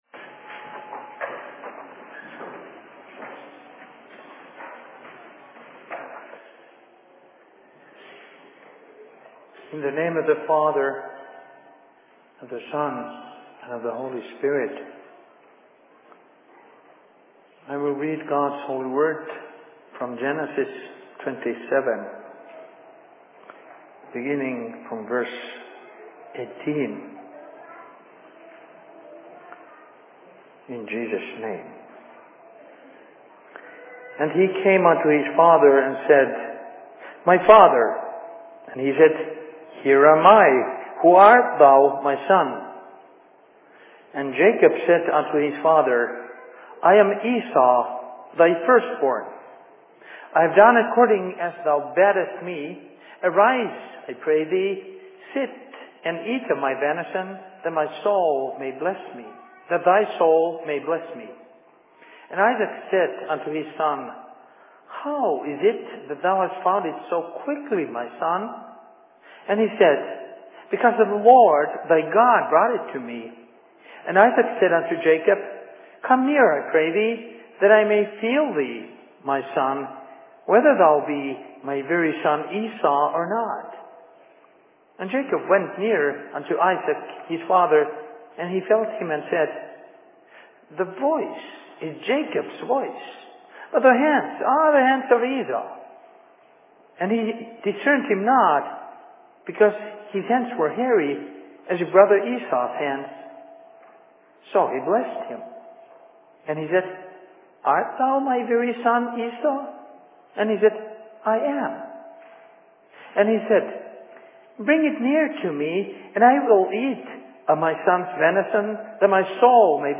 Thanks Giving services/Sermon in Minneapolis 30.11.2008